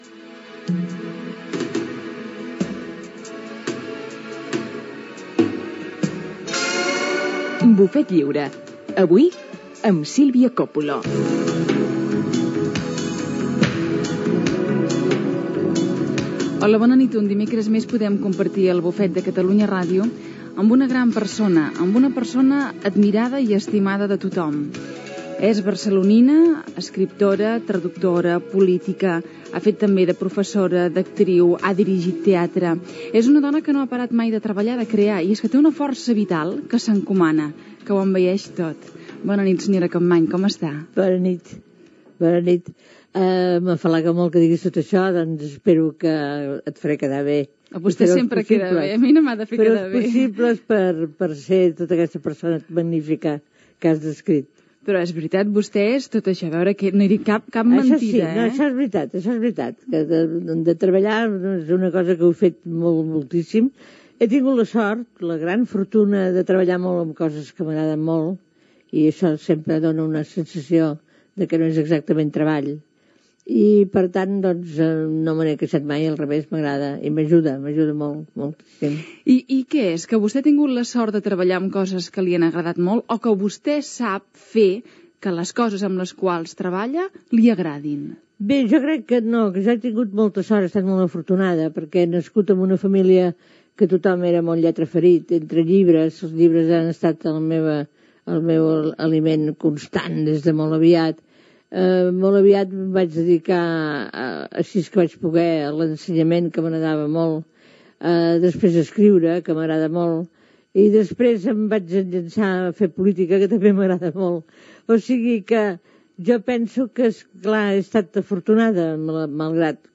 Careta del programa, presentació i entrevista a l'escriptora, traductora i política barcelonina, Maria Aurèlia Capmany.
Entreteniment
FM